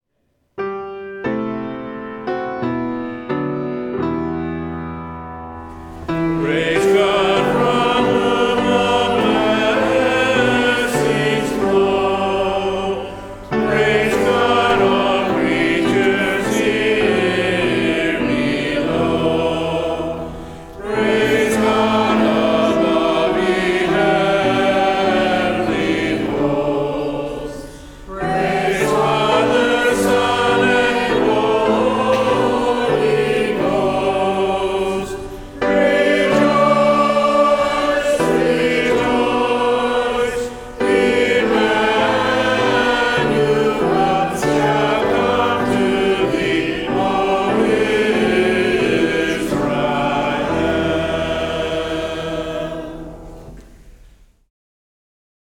Service of Worship